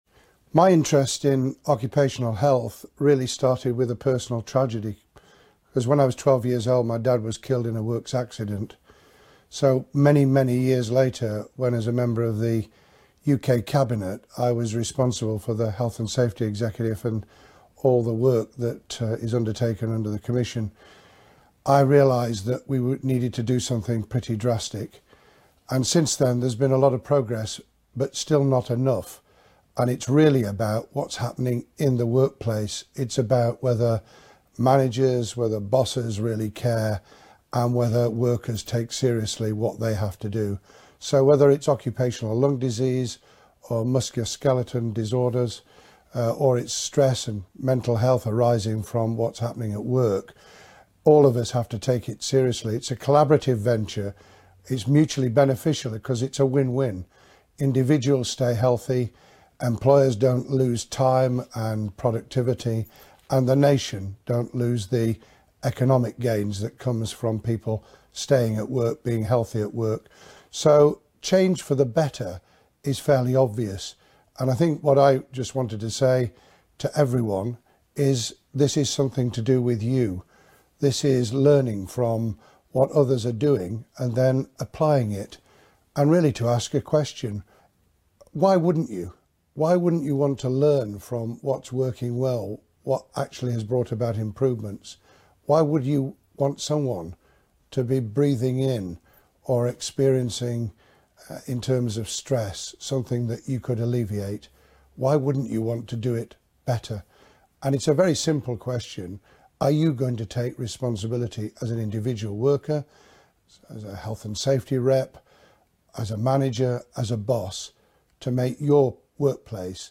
The Rt Hon Lord David Blunkett talks about his personal interest in occupational health and safety and how making improvements in the workplace requires collaboration and benefits everyone.